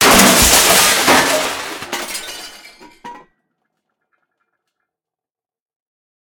combat / armor / break.ogg
break.ogg